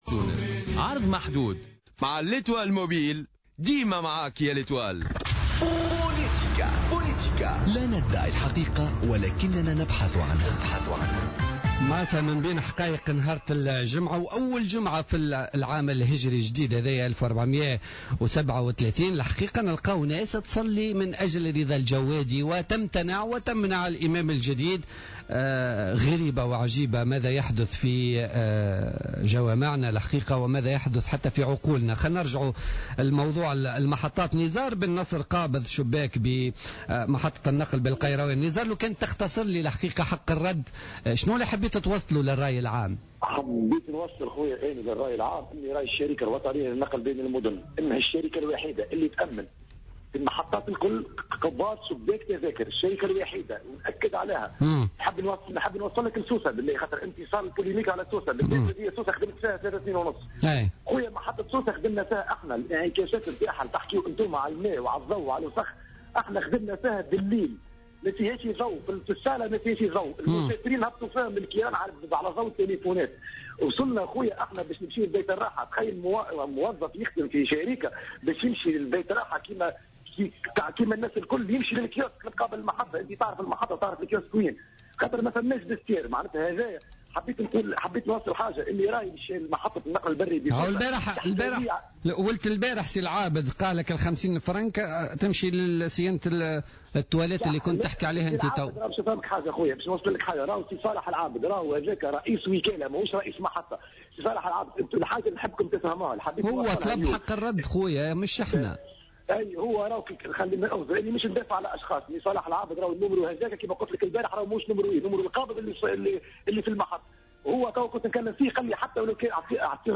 مداخلة في برنامج بوليتيكا